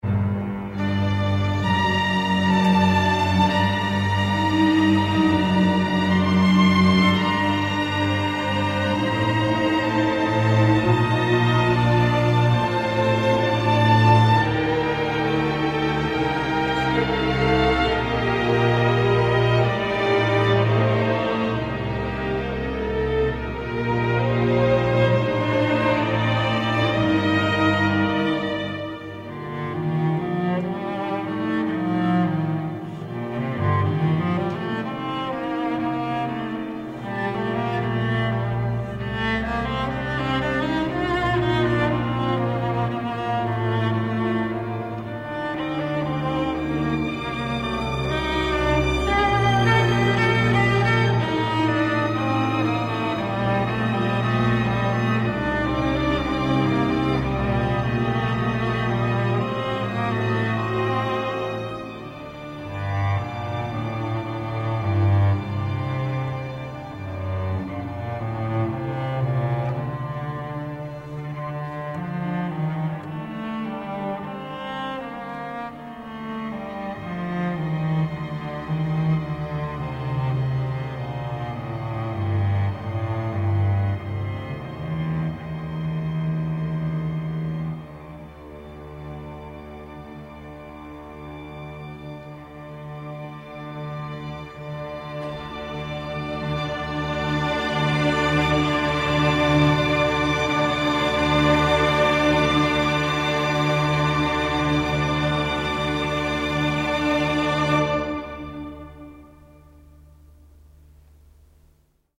wiolonczela